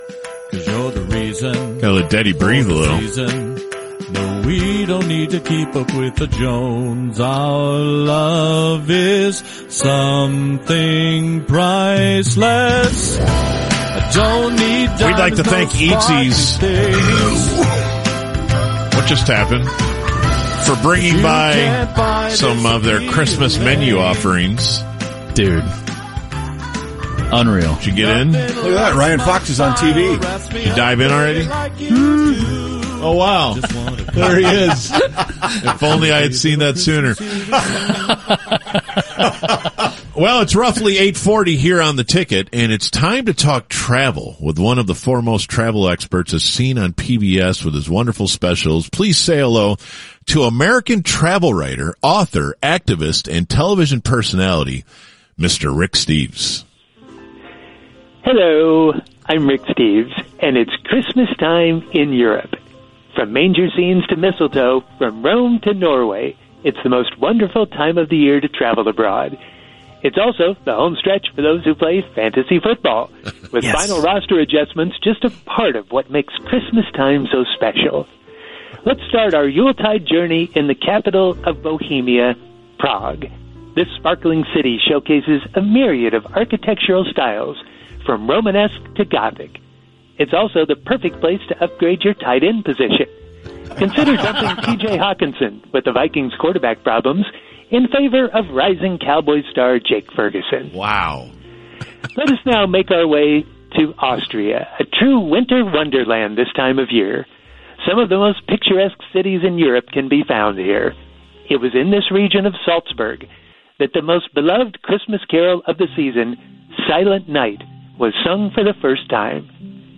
Fake Rick Steves - White Elephant Day 2023 - The UnTicket